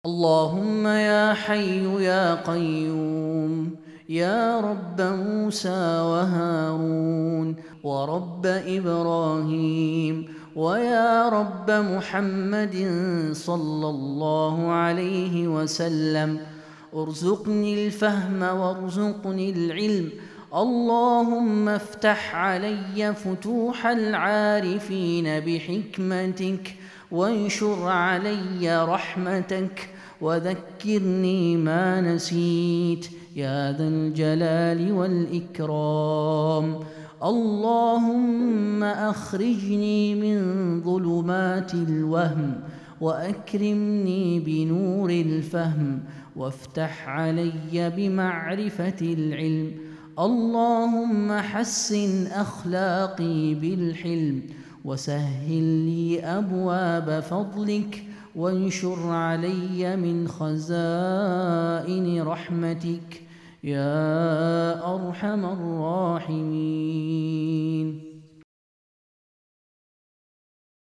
دعاء جامع يطلب فيه الداعي من الله تعالى الفهم والعلم والرزق والرحمة.